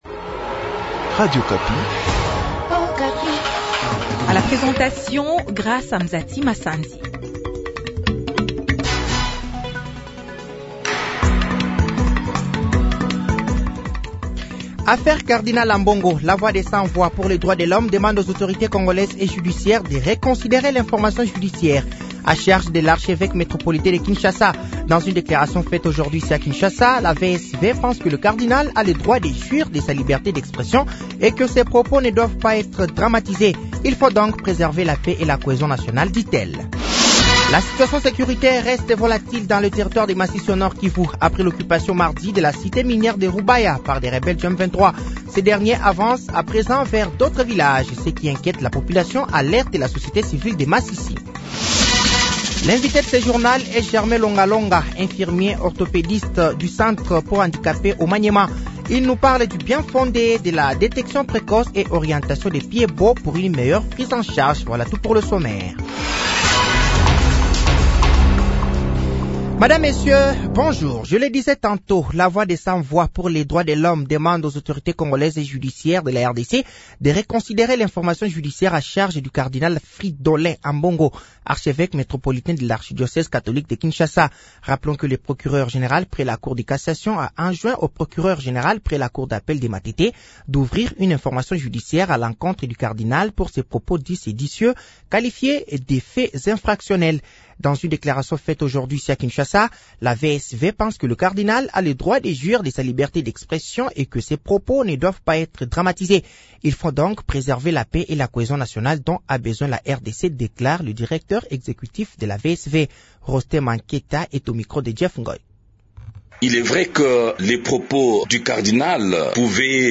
Journal français de 15h de ce jeudi 02 mai 2024